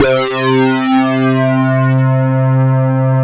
SYN JX P S0C.wav